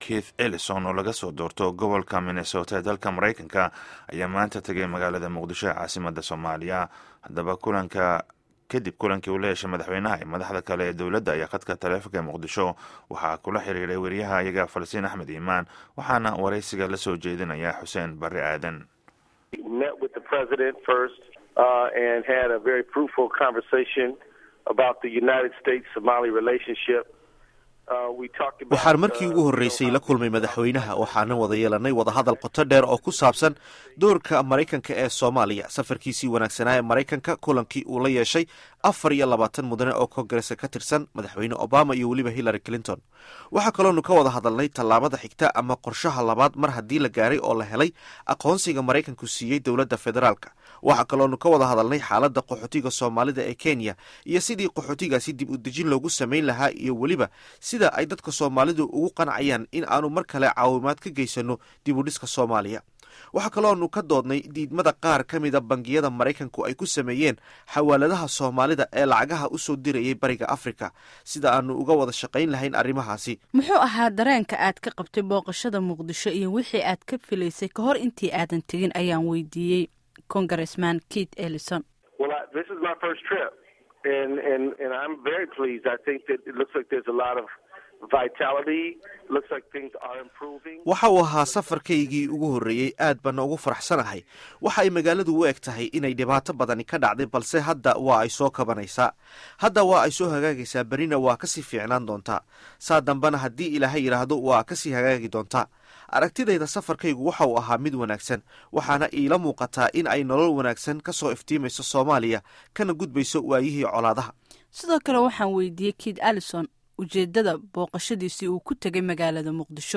Wareysiga Keith Ellison